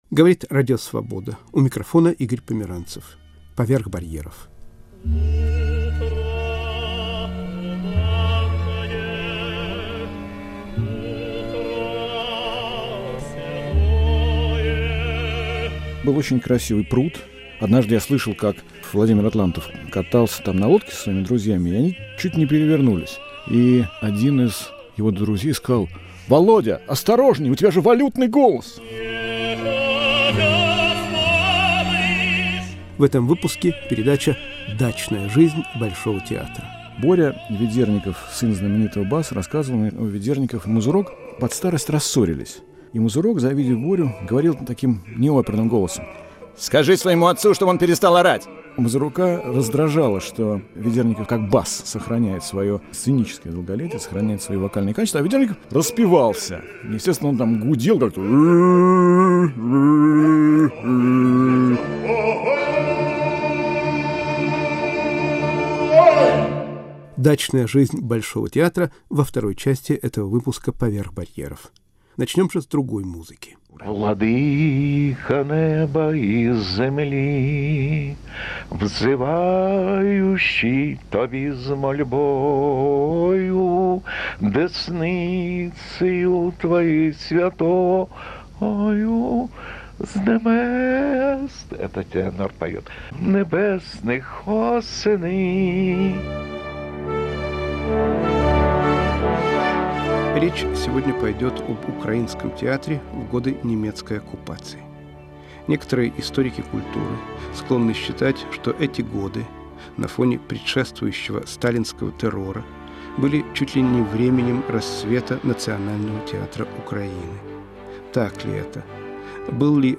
Артисты, пережившие Вторую мировую войну, рассказывают об украинском театре в годы немецкой оккупации (1941-1944)